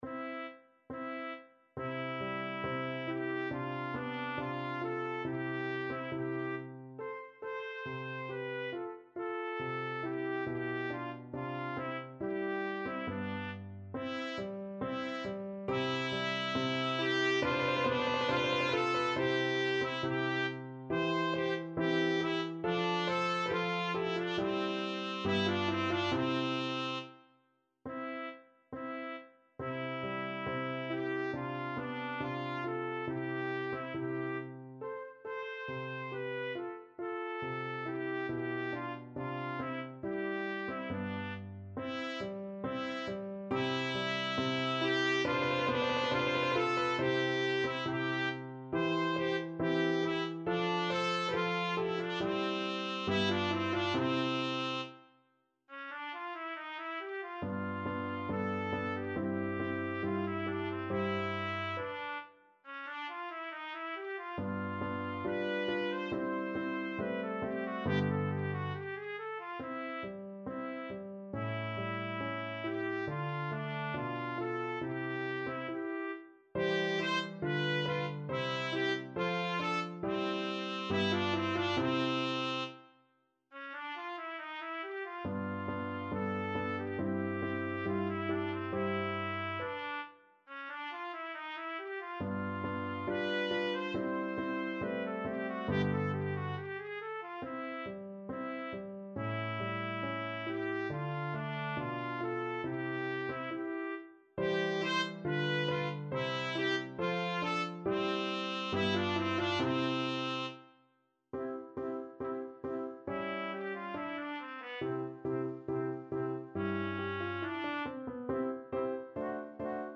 Trumpet version
TrumpetPiano
4/4 (View more 4/4 Music)
Andante =69
Classical (View more Classical Trumpet Music)